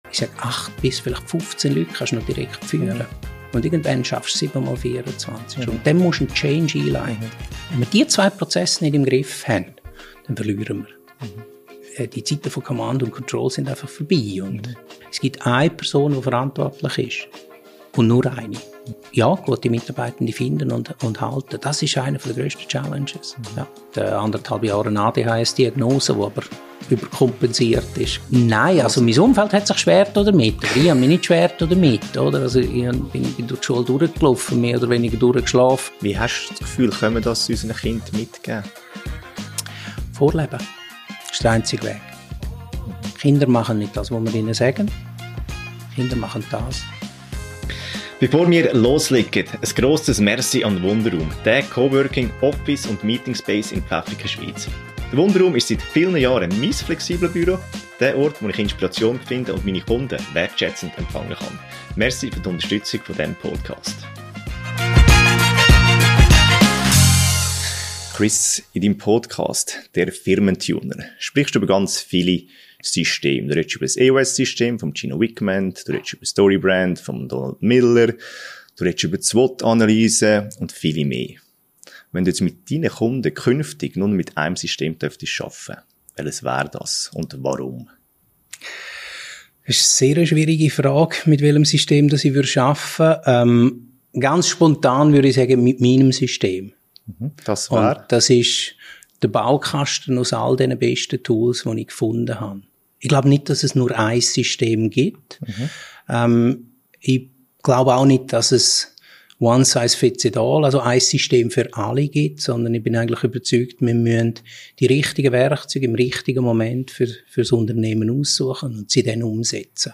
Ein ehrliches und ruhiges Gespräch über Führung auf Augenhöhe, «bescheiden selbstbewusst» sein und die Erkenntnis, dass nicht Technik die grösste Herausforderung ist, sondern Menschen.